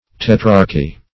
Meaning of tetrarchy. tetrarchy synonyms, pronunciation, spelling and more from Free Dictionary.
Search Result for " tetrarchy" : The Collaborative International Dictionary of English v.0.48: Tetrarchy \Tet"rarch*y\, n.; pl.